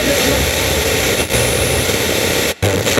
80BPM RAD1-R.wav